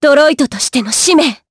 Lorraine-Vox_Skill5_jp.wav